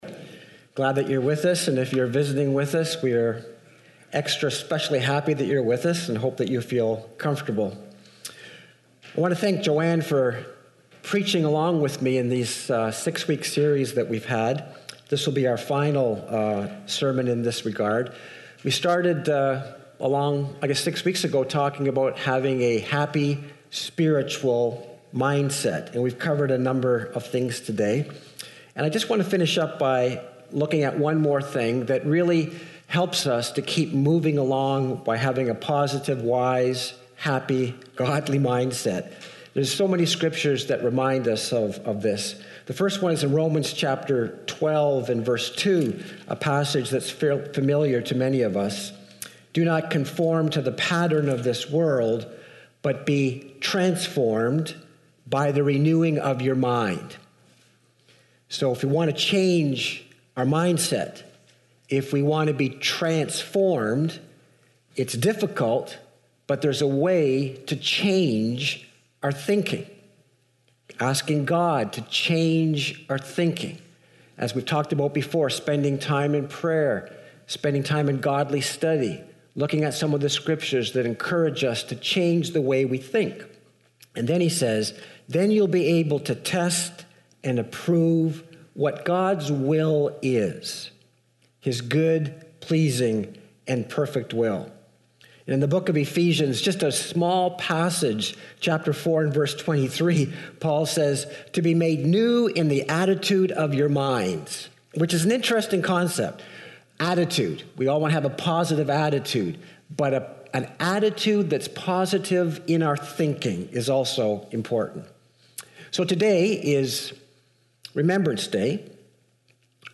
Please be aware, today’s sermon contains discussion of depression and suicide.